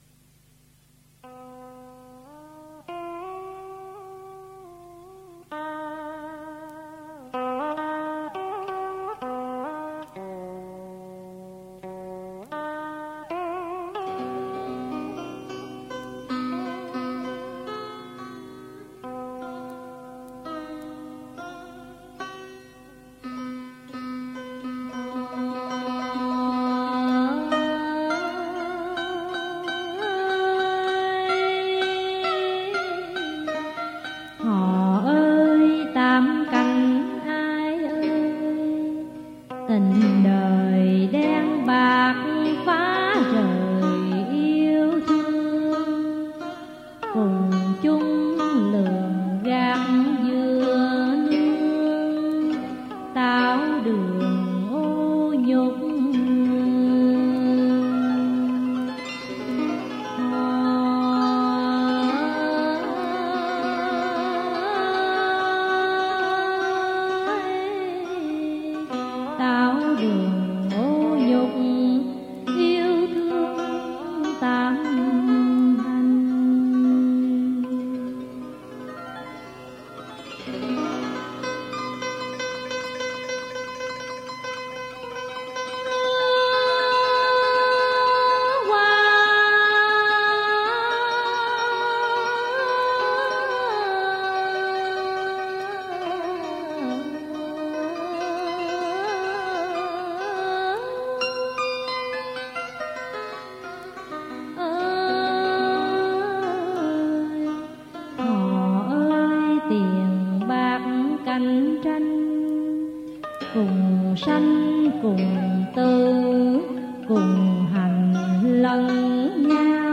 Dân Ca & Cải Lương
(theo điệu hò miền Nam)